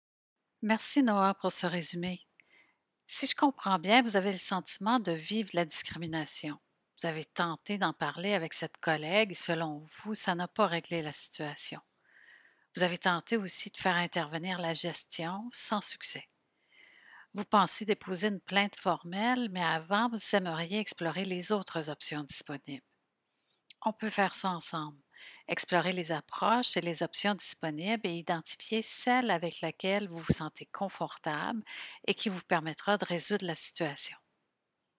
Ces entretiens fictifs sont basés sur un amalgame d’expériences vécues.
un employé qui a le sentiment de vivre de la discrimination.